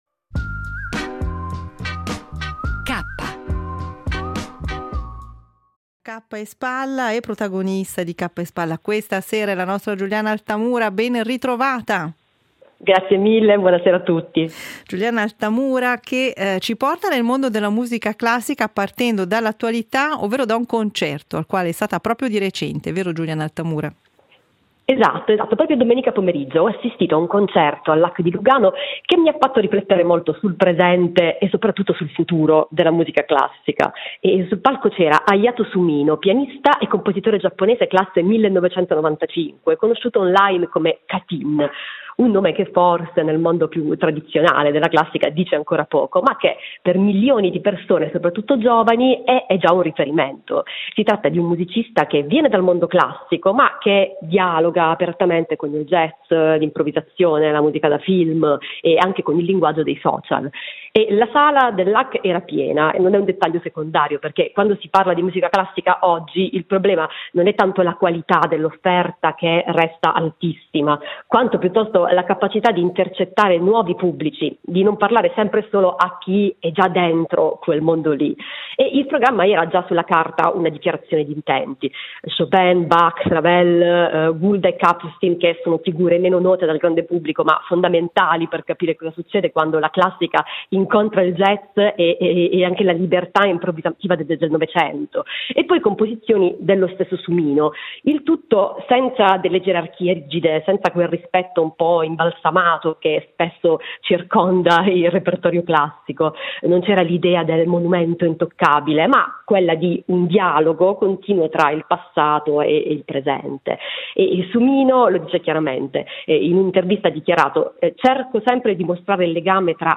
Editoriali tra cultura, attualità e sguardi sul costume